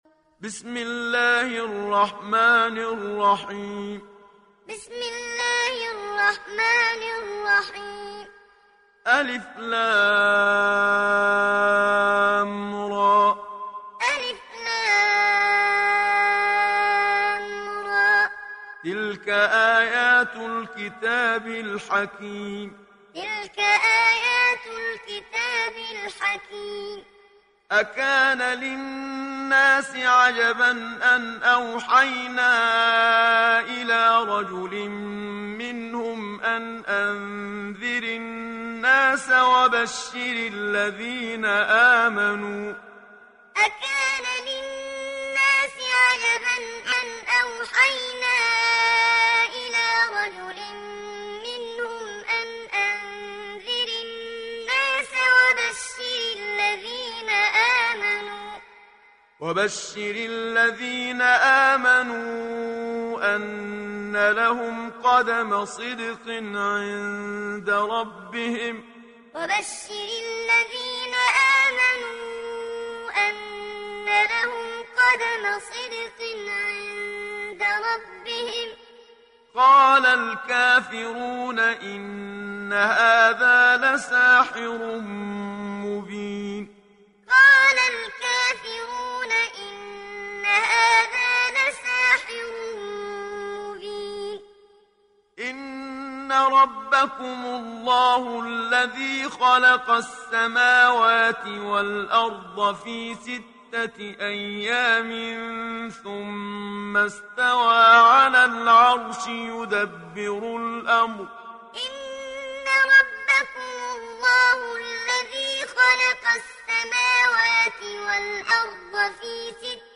تحميل سورة يونس mp3 بصوت محمد صديق المنشاوي معلم برواية حفص عن عاصم, تحميل استماع القرآن الكريم على الجوال mp3 كاملا بروابط مباشرة وسريعة
تحميل سورة يونس محمد صديق المنشاوي معلم